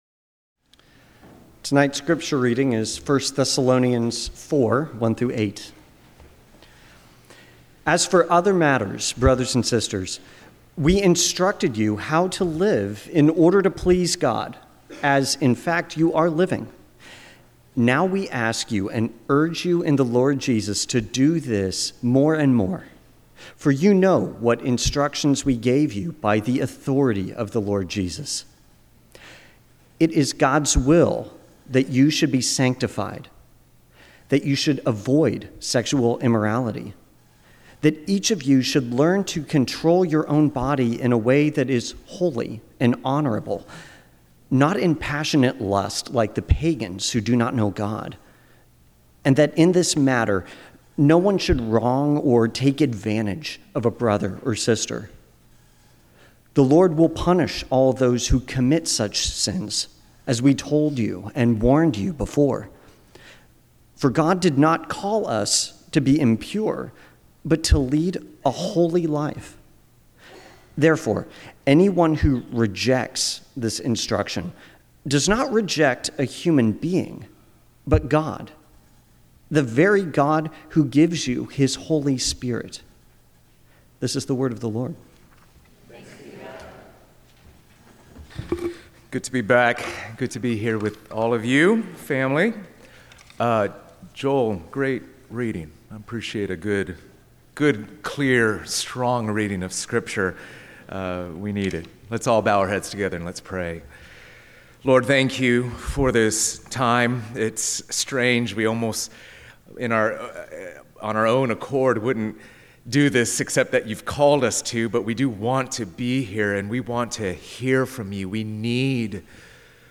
Seven Deadly Sins Share Related Sermons Why Do We Seek the Living Among the Dead?